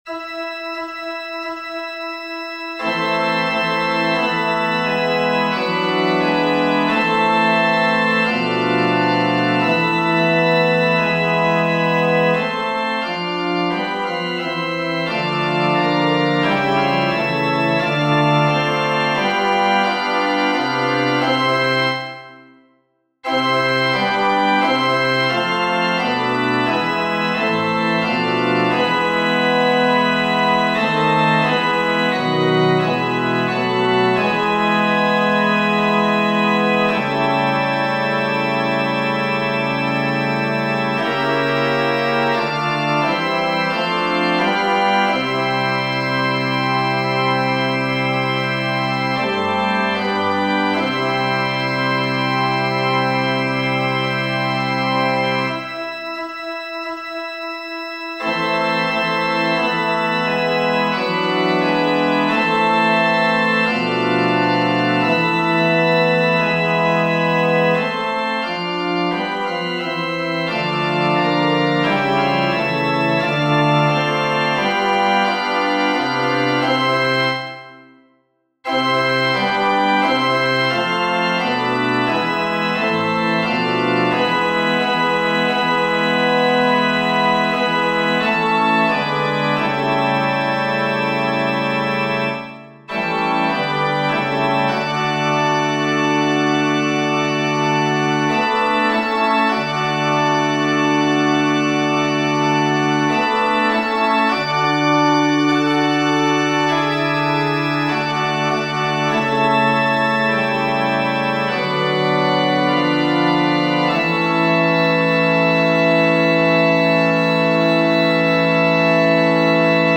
FF:HV_15b Collegium male choir